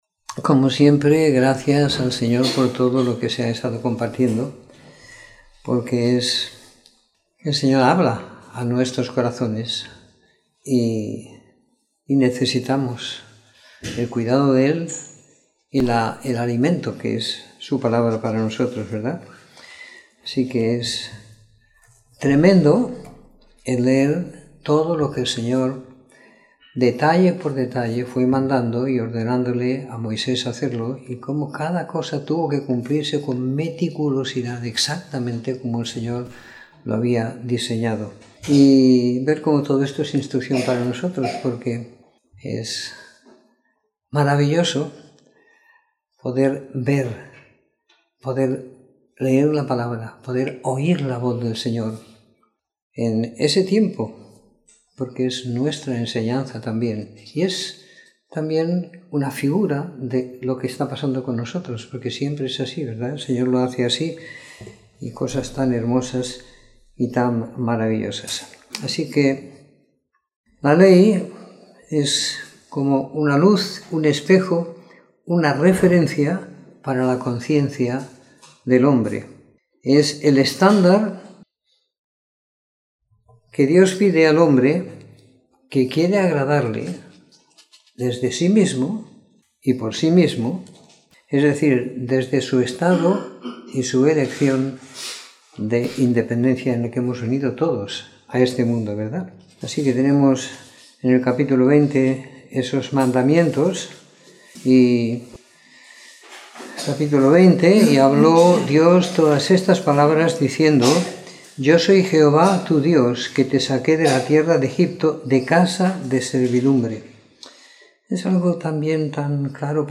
Comentario en Éxodo 19-40 - 08 de Febrero de 2019